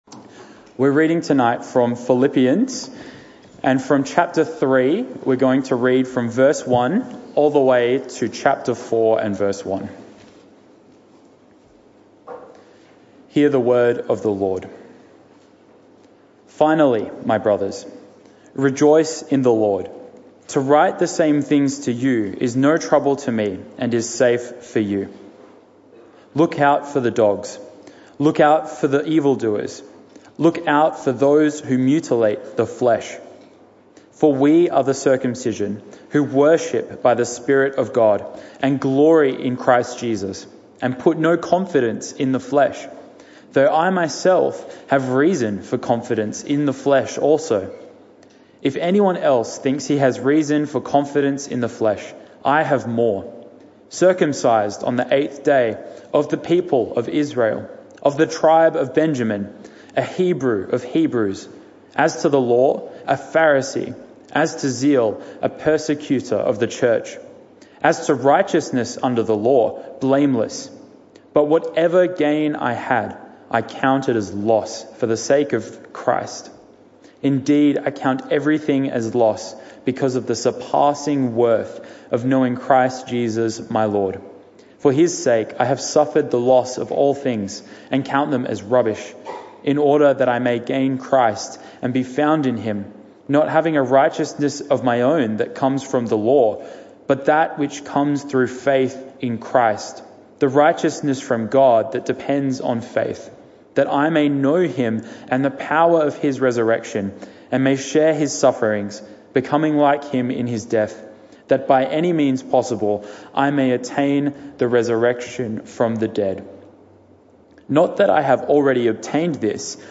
This talk was part of the PM Service series entitled This Is The Way.